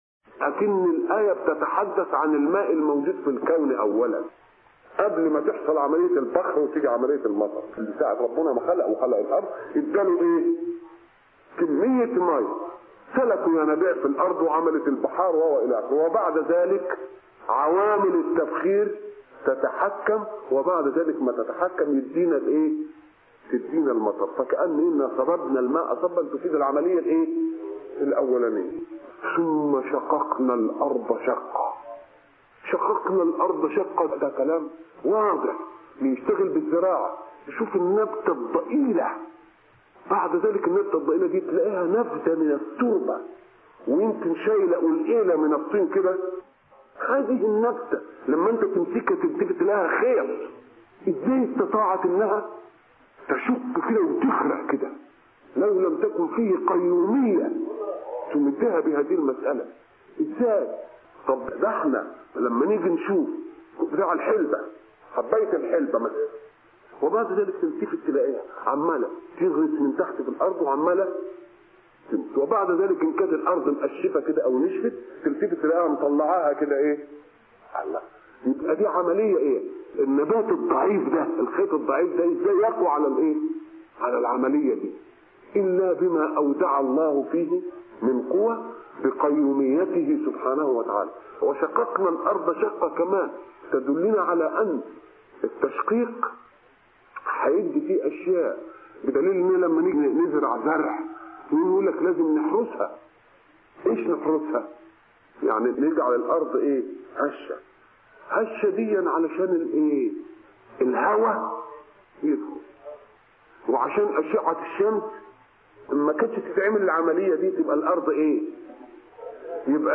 أرشيف الإسلام - ~ أرشيف صوتي لدروس وخطب ومحاضرات الشيخ محمد متولي الشعراوي